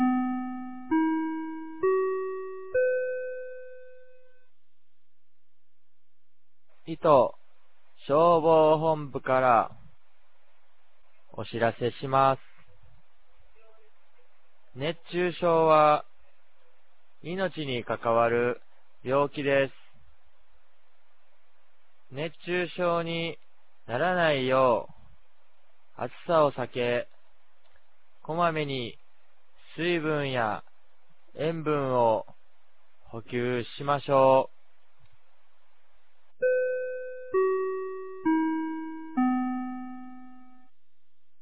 2025年07月22日 10時00分に、九度山町より全地区へ放送がありました。
放送音声